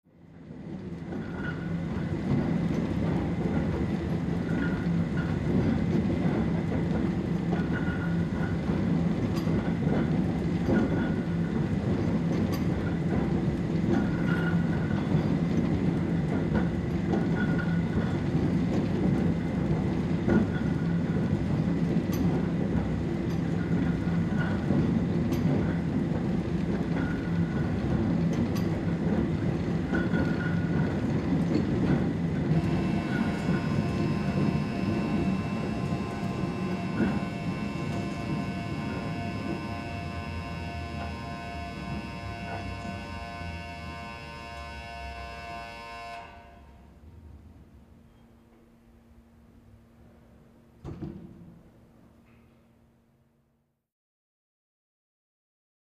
Cable Car Barn, W Slow Turning Machinery, Squeaky Wheels, Warning Buzzer.